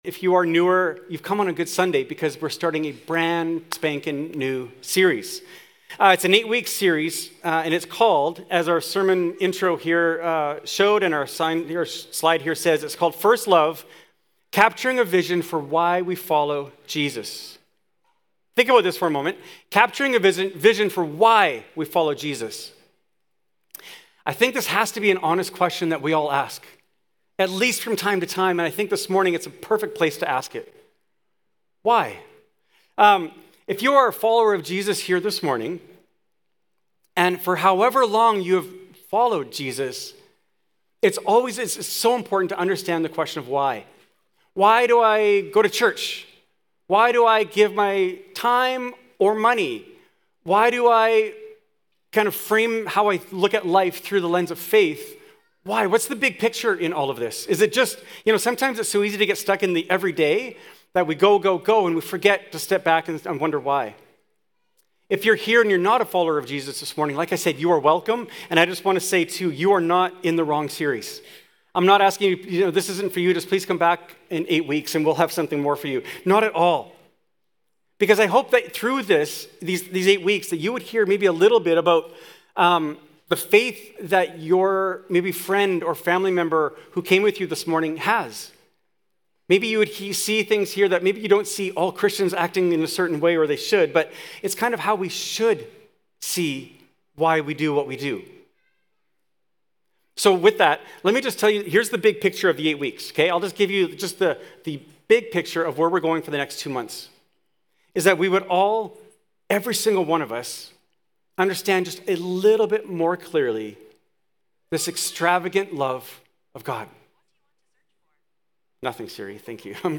Yorkson Sermons | North Langley Community Church